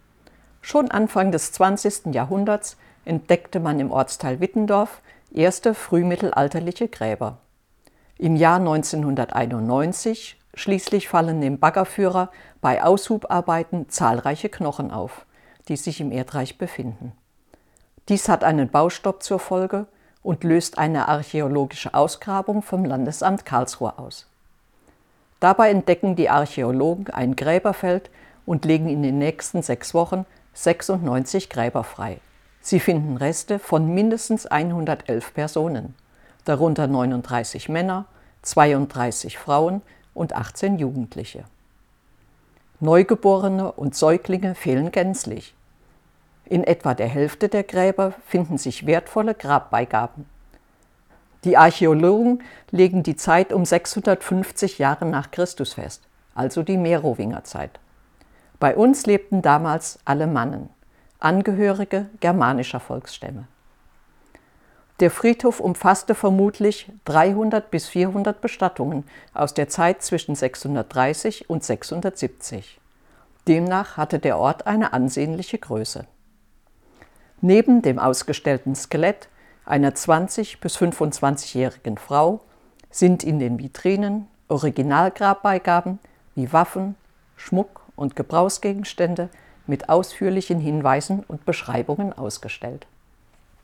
Erleben Sie einen interessanten Rundgang durch unser Heimatmuseum "Altes Rathaus" in Loßburg und lassen Sie sich mit unserem Audioguide durch Raum und Zeit begleiten.